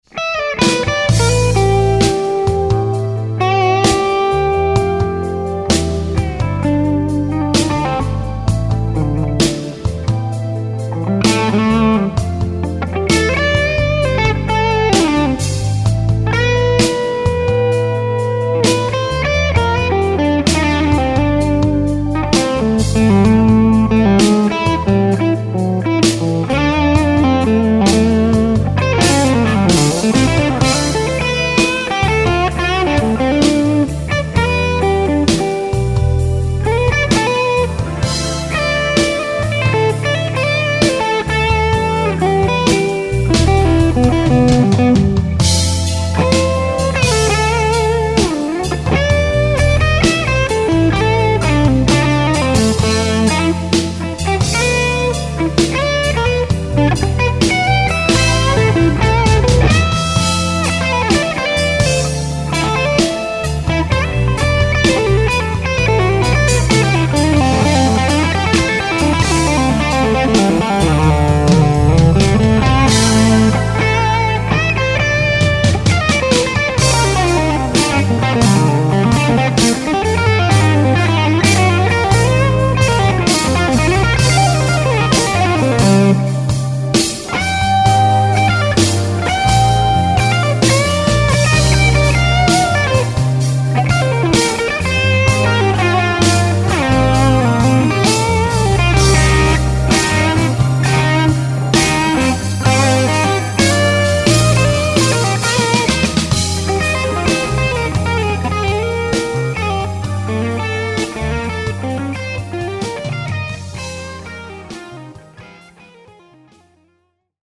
Here is an HRM clip I did today. Just testing stuff. 6L6 tubes, standard PI, PAB engaged. Seems pretty smooth....
Gives an almost living human quality to their "voice". Kind of a "chesty" tone.
SED winged C 6L6. 2 of them.
That was a Celestion G1265 and SM57 mic.....